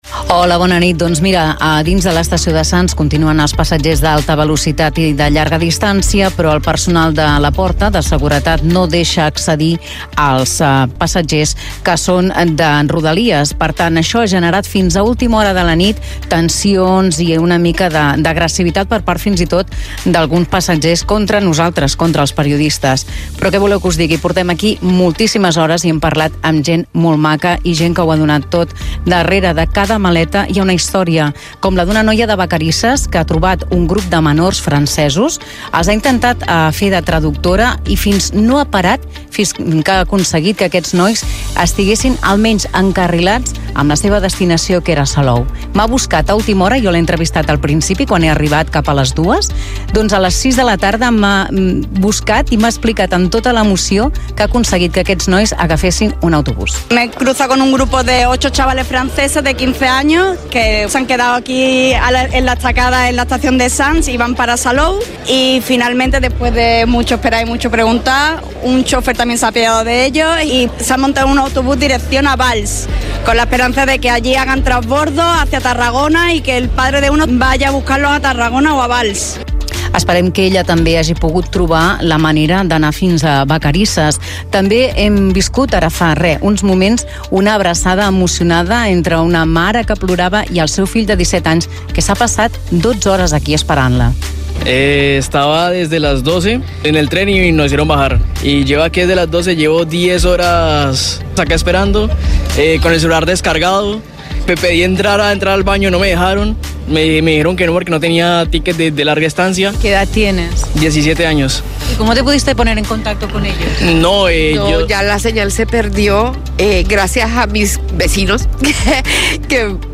Especial apagada - Catalunya Ràdio, 2025